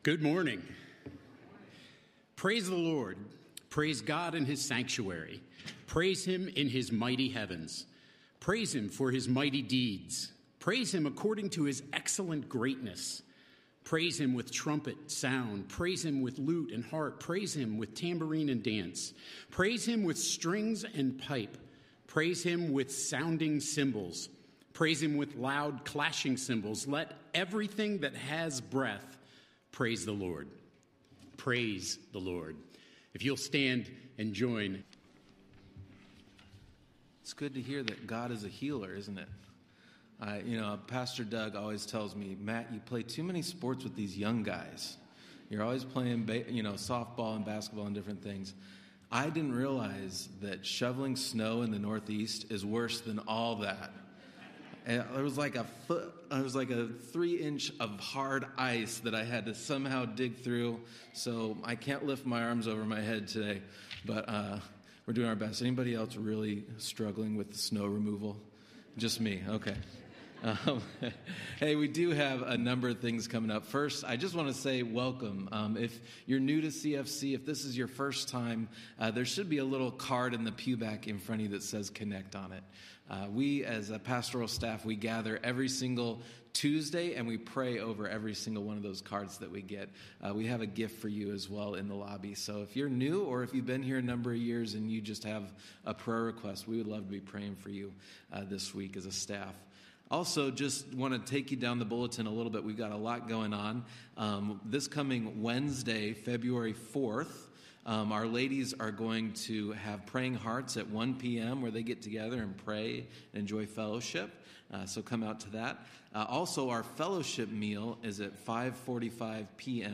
Sermons | Christian Fellowship Church